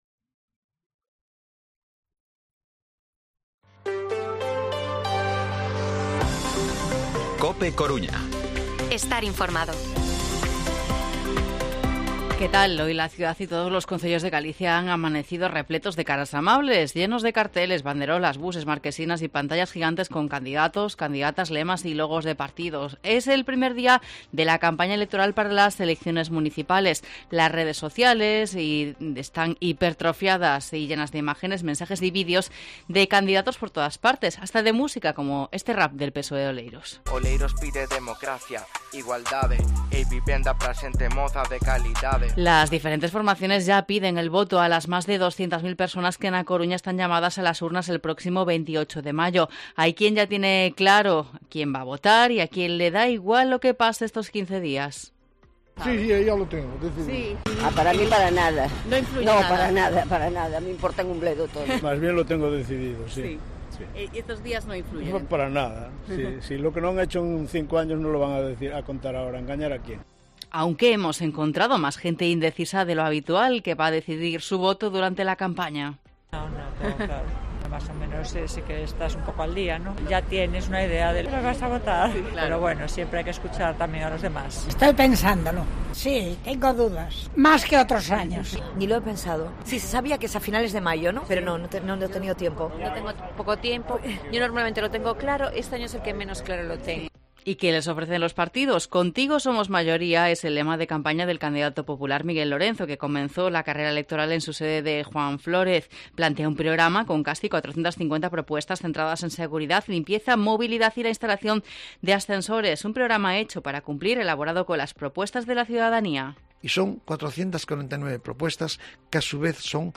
Informativo Mediodía COPE Coruña viernes, 12 de mayo de 2023 14:20-14:30